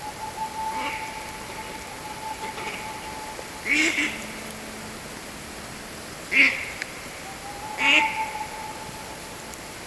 кряква, Anas platyrhynchos
СтатусПребывает в подходящем для гнездования биотопе